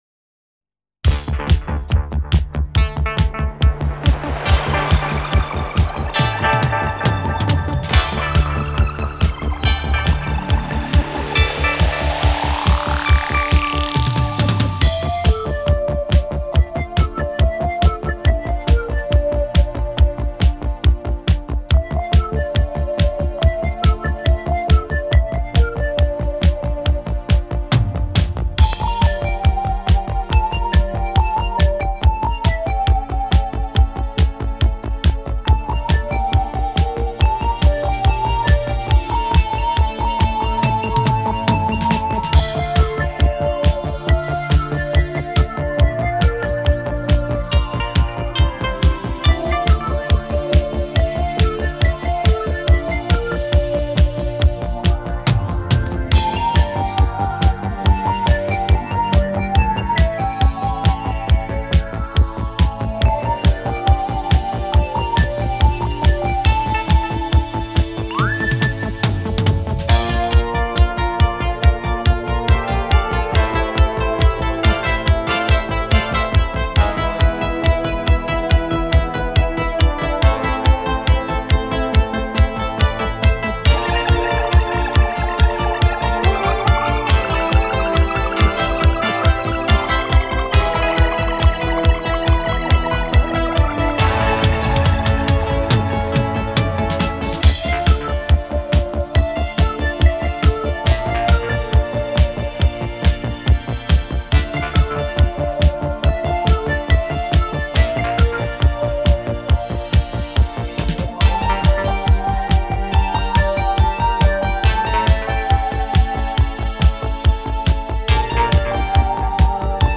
Жанр: Electronic; Битрэйт